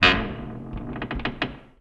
metal_low_creak_squeak_06.wav